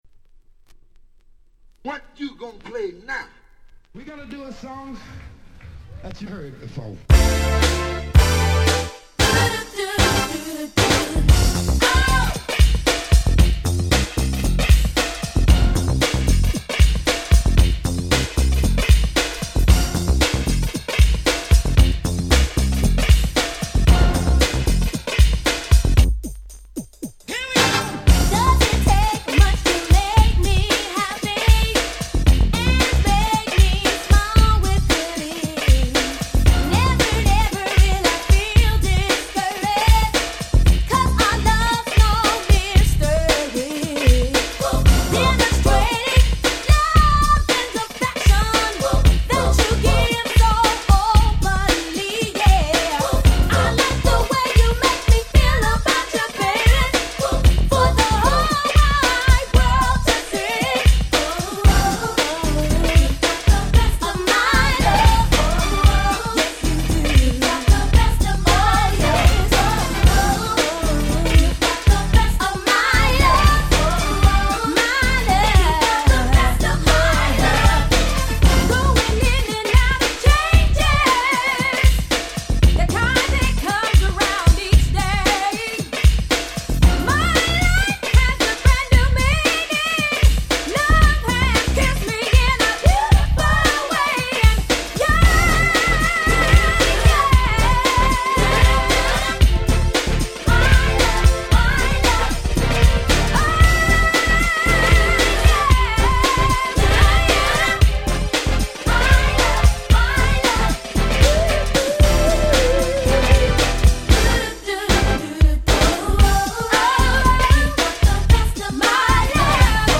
91' Nice Girls New Jack Swing !!